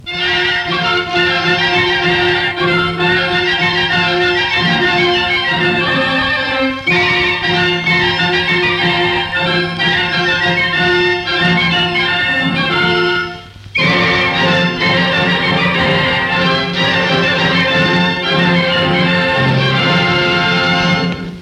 Primera sintonia de l'emissora